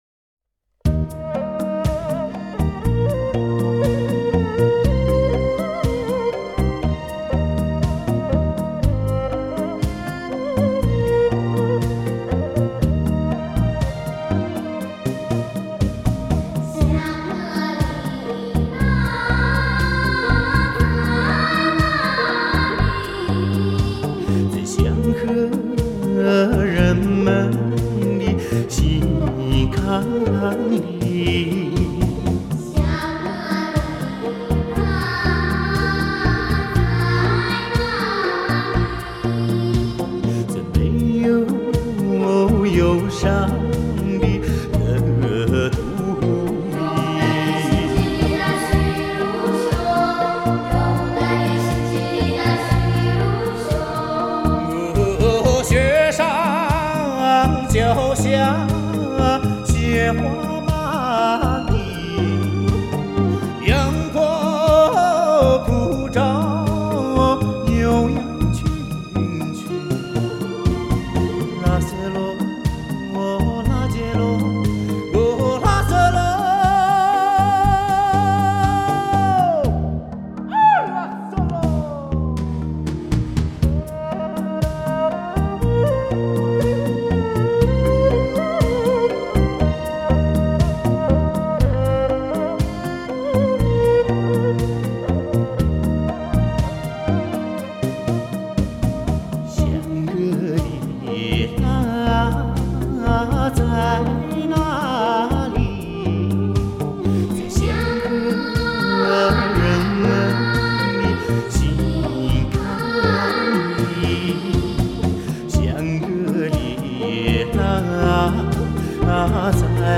新音乐